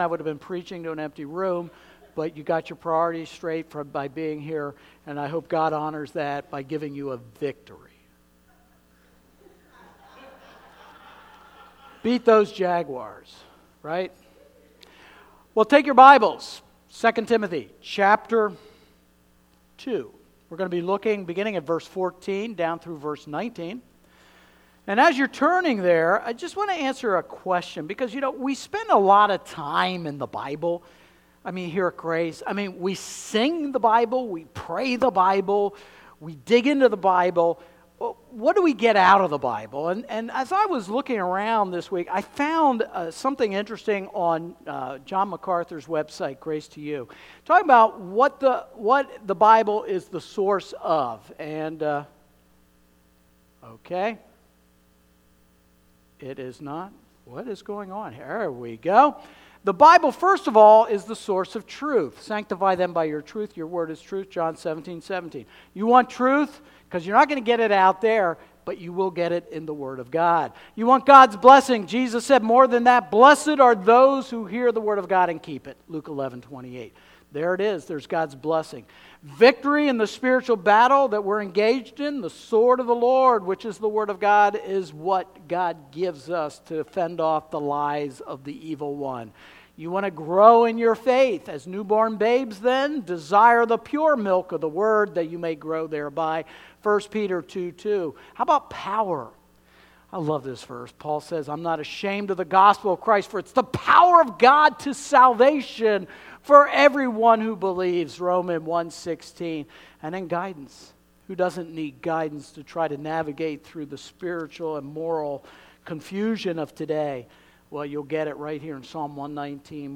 Sermon PowerpointDownload